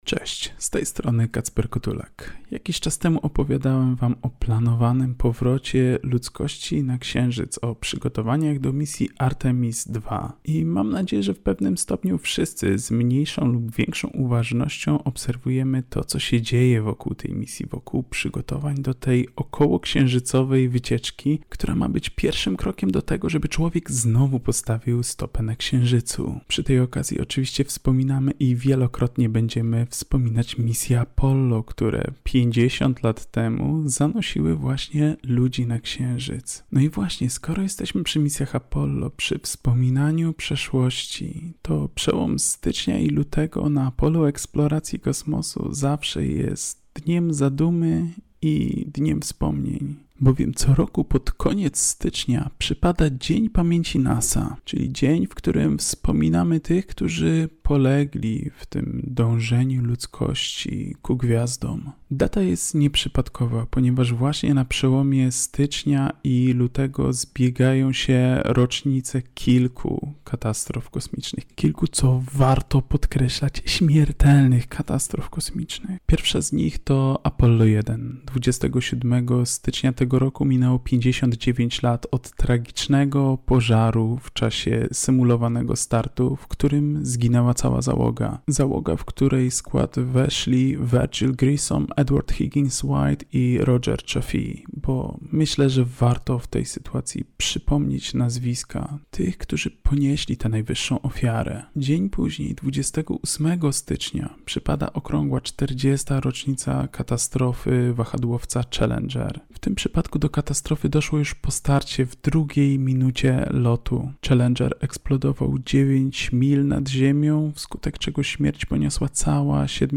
Postój z Nauką robimy sobie na antenie Radia UWM FM od poniedziałku do czwartku około 14:15 w audycji Podwójne Espresso.